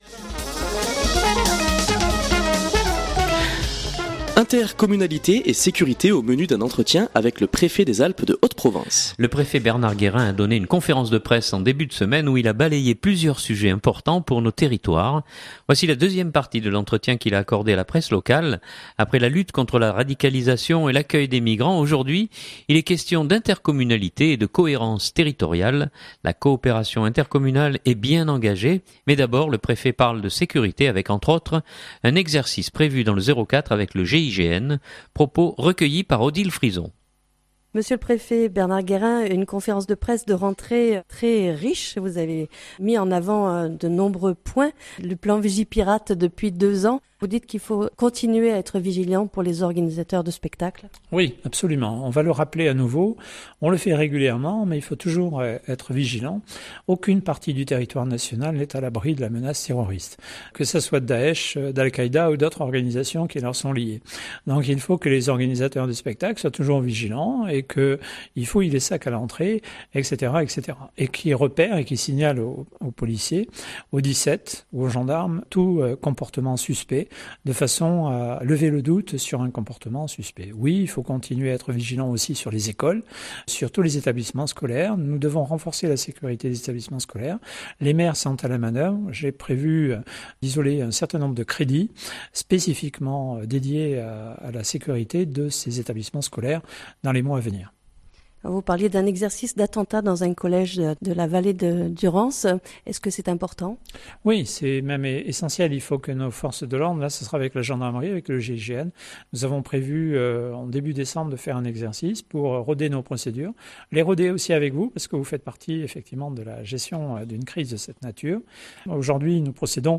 Voici la deuxième partie de l’entretien qu’il a accordé à la presse locale.